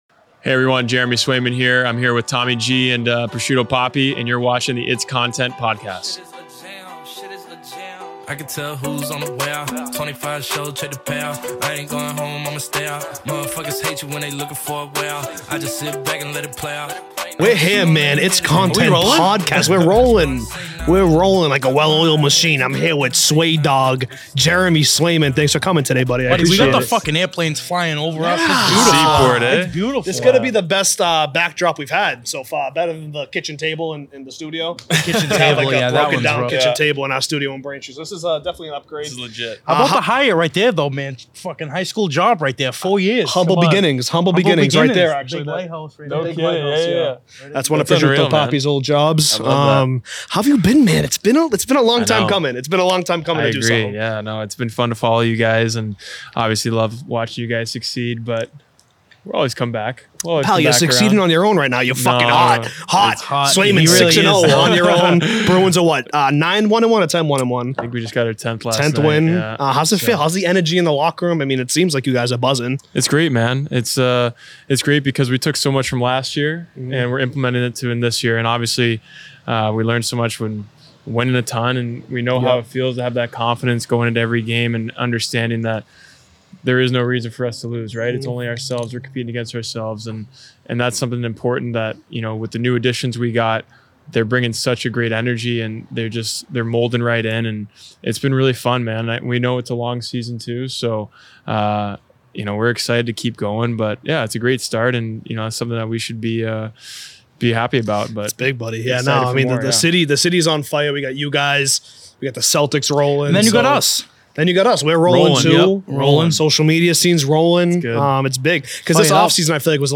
The boys had Boston Bruins goalie and Avid camper, Jeremy Swayman on the pod.